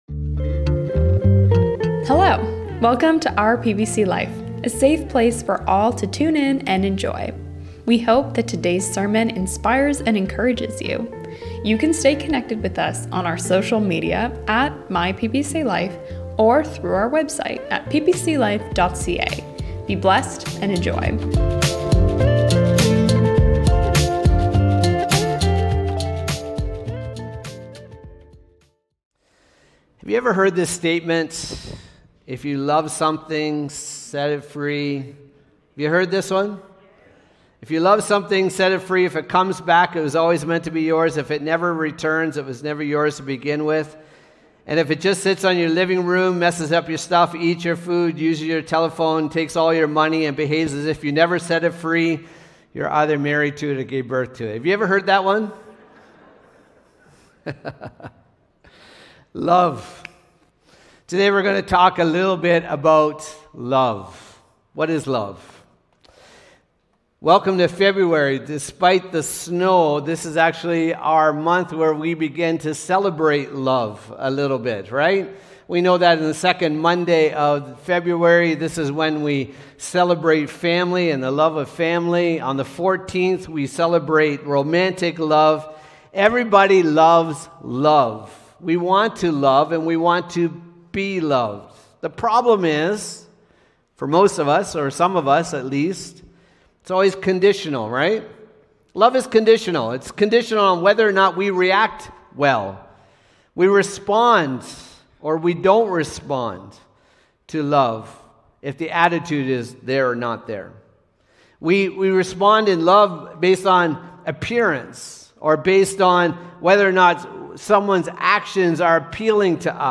In today's sermon